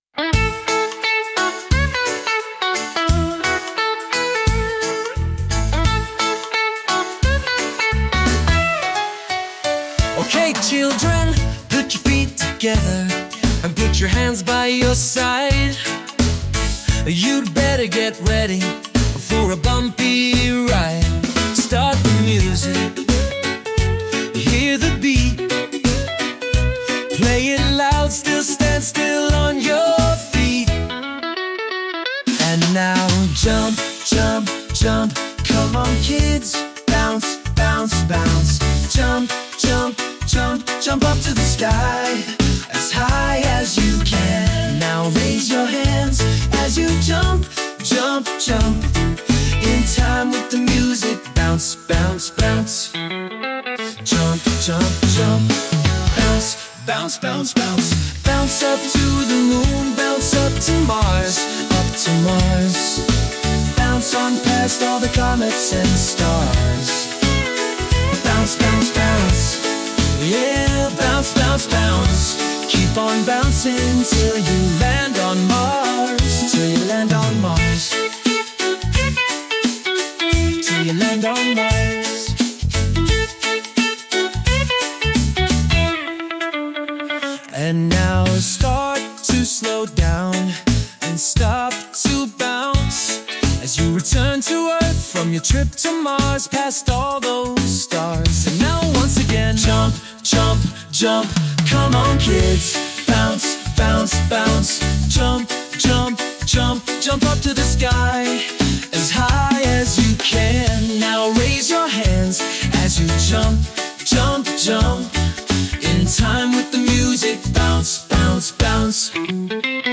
This children’s song
energetic and imaginative musical journey